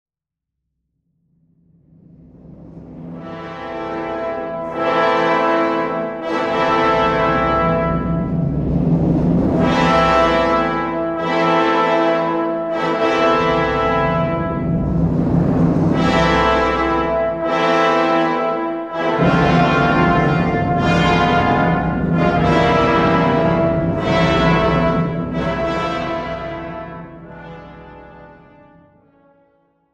Classical and Opera